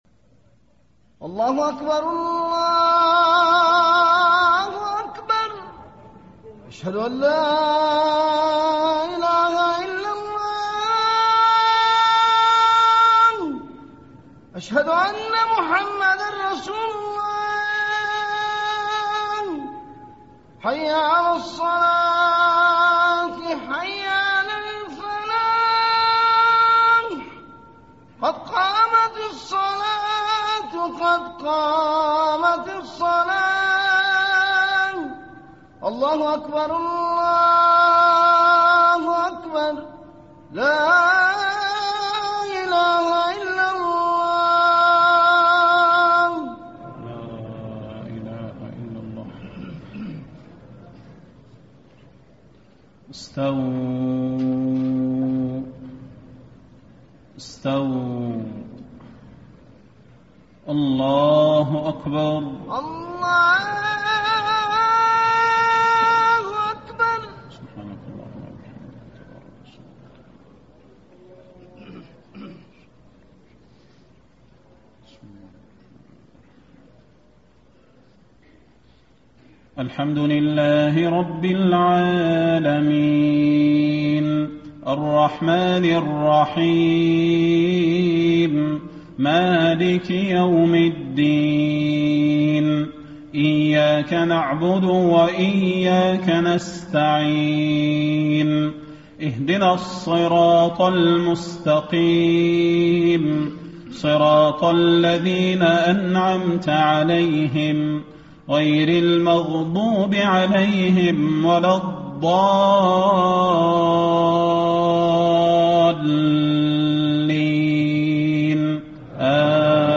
عشاء 13 شعبان ١٤٣٥ من سورة النحل > 1435 🕌 > الفروض - تلاوات الحرمين